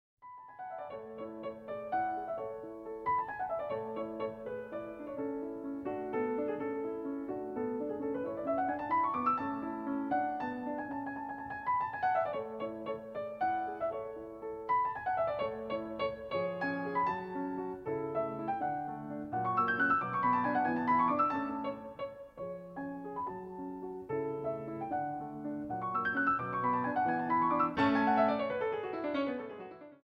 Allegro 7:56